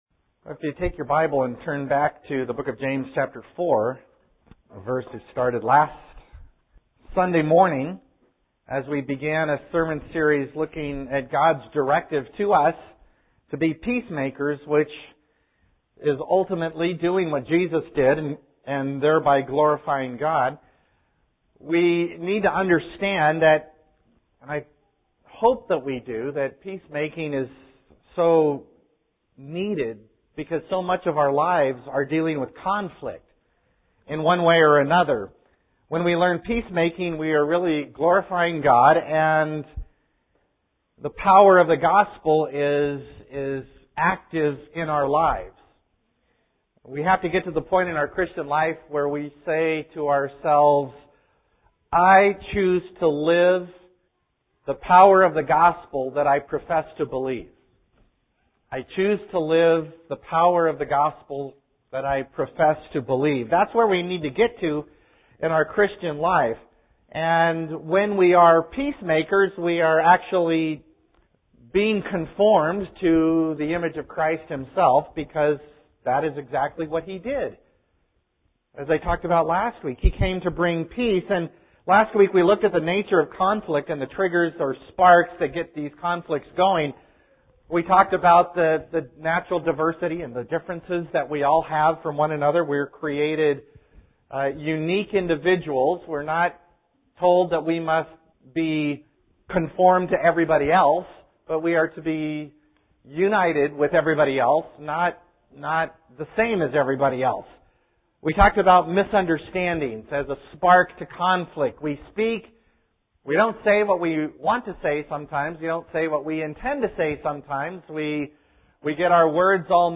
1st Congregational Chuch Kulm - Sermon Archive